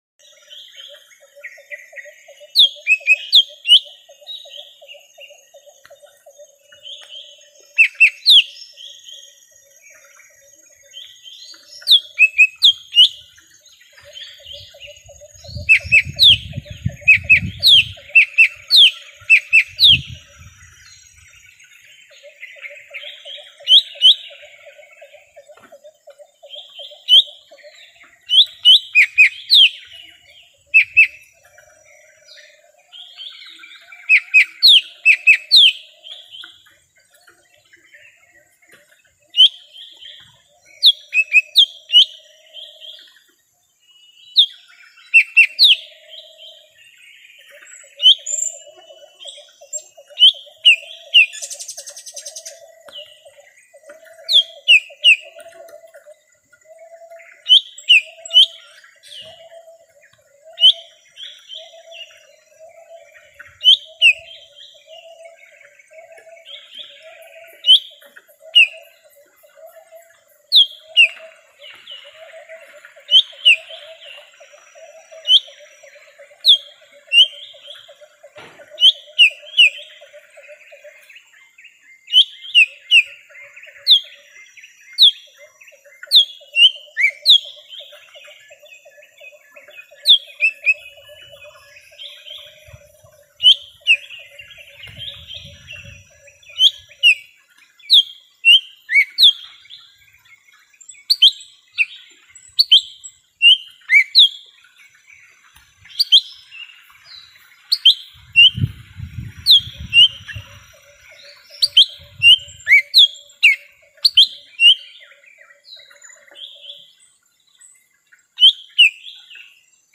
Suara Cucak Ijo Mini Asli Hutan
Kategori: Suara burung
suara-burung-cucak-ijo-mini-asli-hutan-id-www_tiengdong_com.mp3